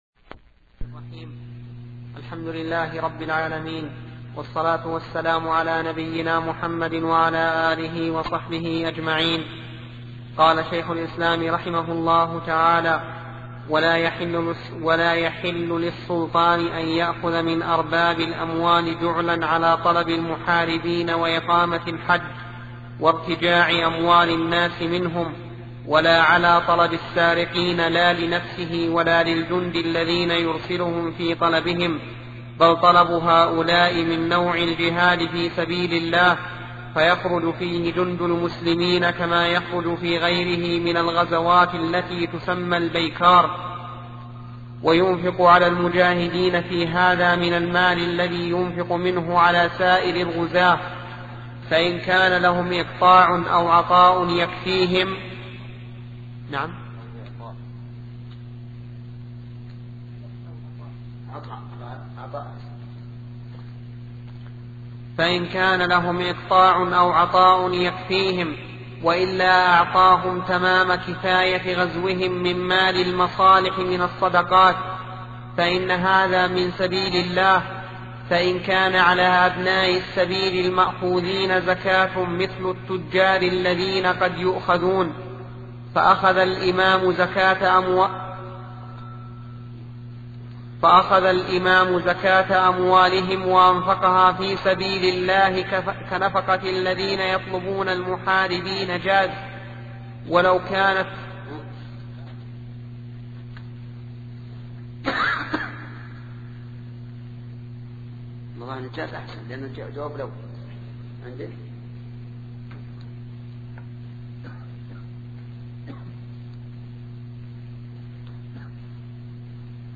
سلسلة مجموعة محاضرات التعليق على السياسة الشرعية لابن تيمية لشيخ محمد بن صالح العثيمين رحمة الله تعالى